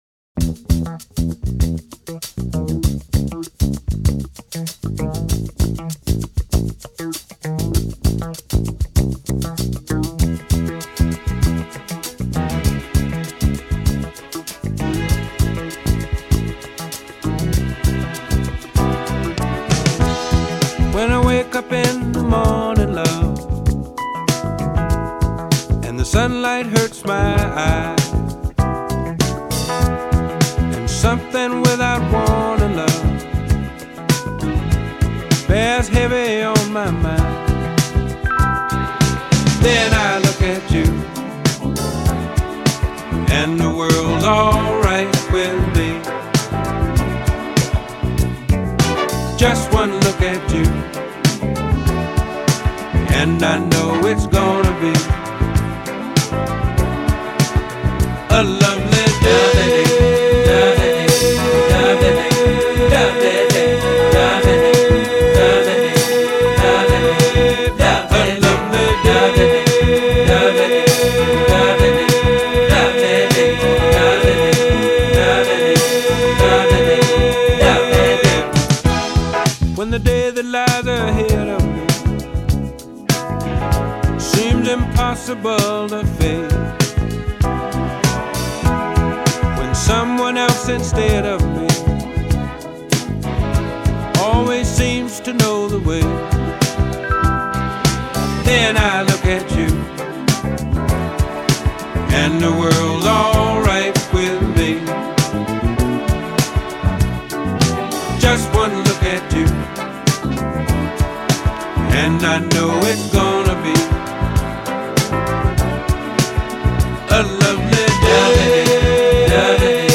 Who holds a note for eighteen seconds?